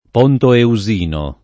[ p 0 nto eu @& no ]